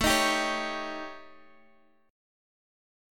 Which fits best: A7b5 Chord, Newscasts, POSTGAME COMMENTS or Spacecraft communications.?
A7b5 Chord